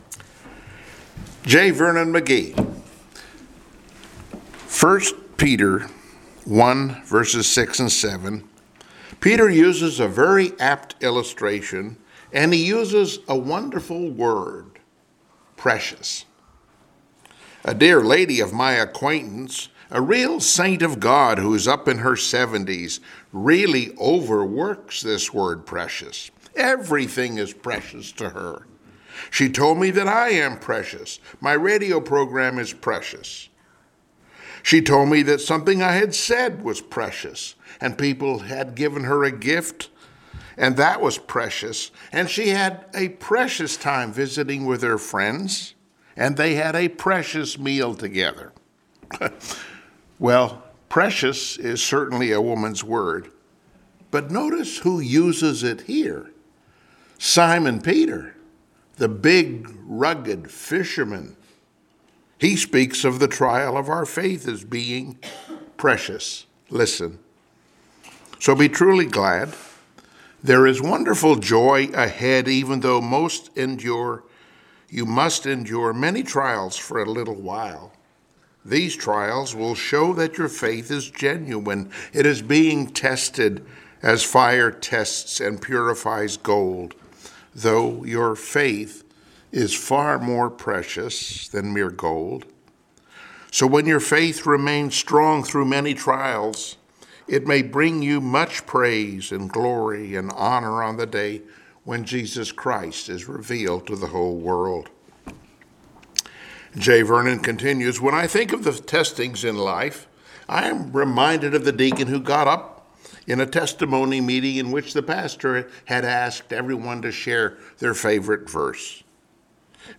Passage: James 1:4-12 Service Type: Sunday Morning Worship